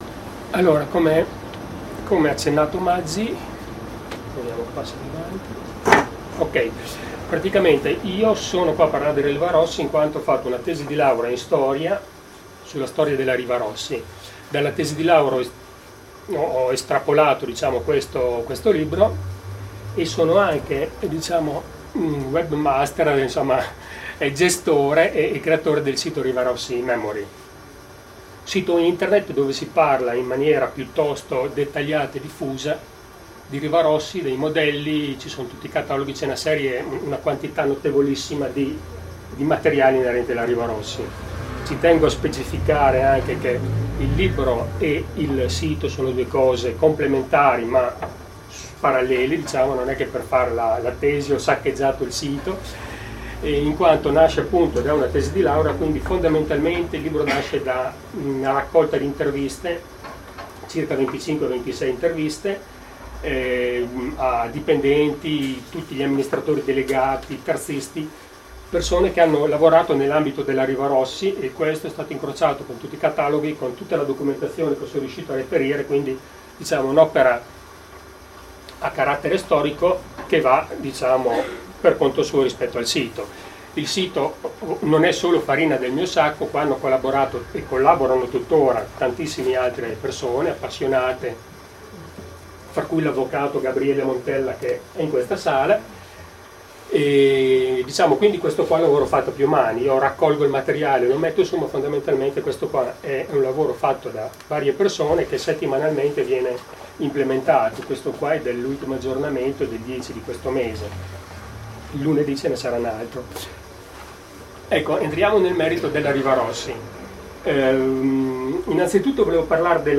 È disponibile la registrazione audio della conferenza del 21 ottobre 2011: